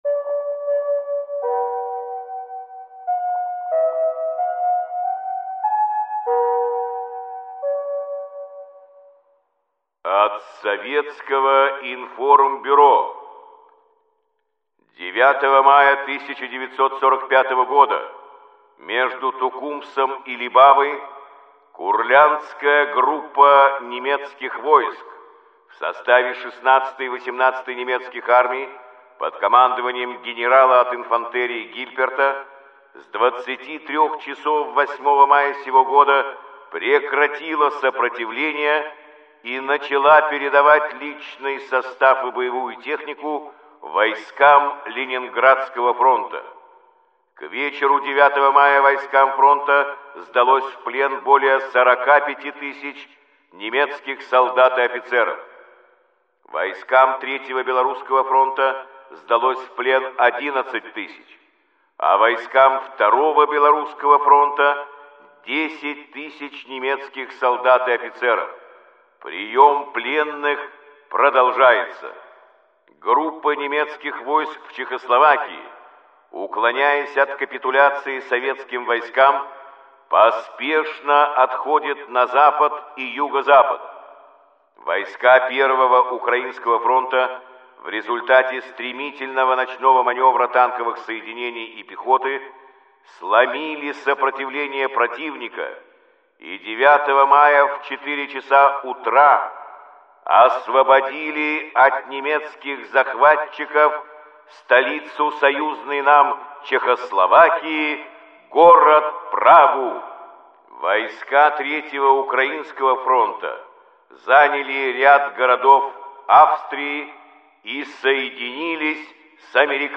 Каждый день – это хронология самых важных событий и подвигов героев Великой Отечественной войны, рассказанная в сообщениях СОВИНФОРМБЮРО голосом Юрия Борисовича Левитана.